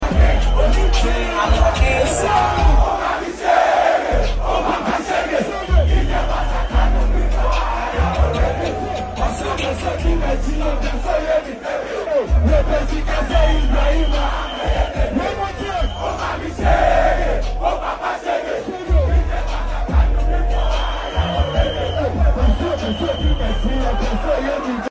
on stage!